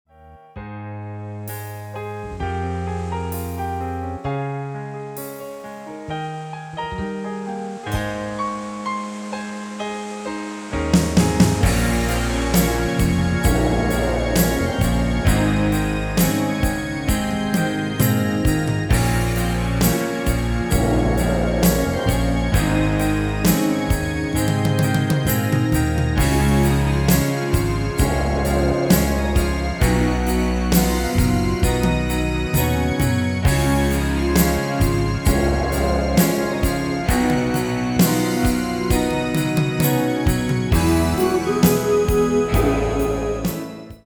Demo/Kauf Midifile
Stil: Evergreens & Oldies
Tonart: Bbm